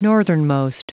Prononciation du mot northernmost en anglais (fichier audio)
Prononciation du mot : northernmost